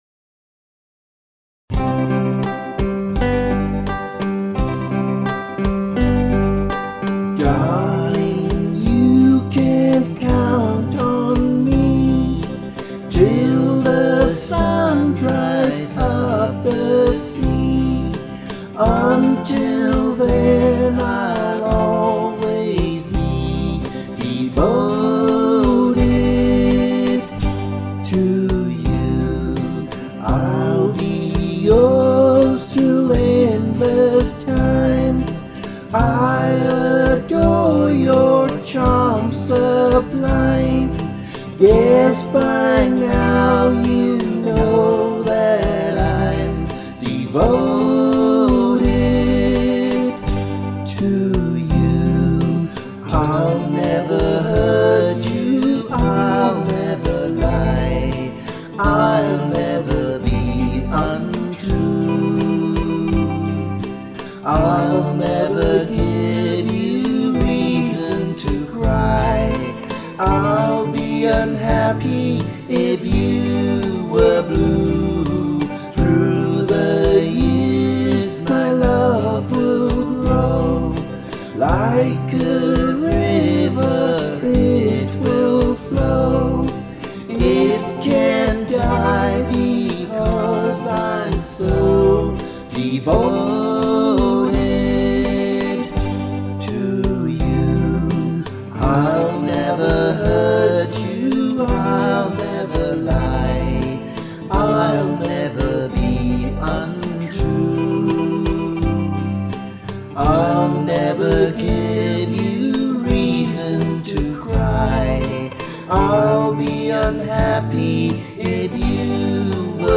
Recorded on separate continents